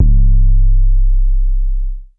Gucci 808.wav